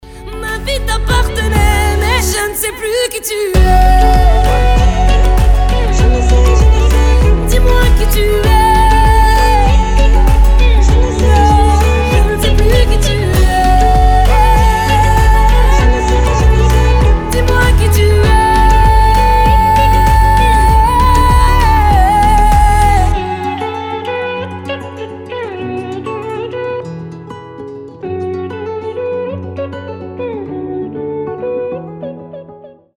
• Качество: 320, Stereo
поп
мелодичные
красивый женский вокал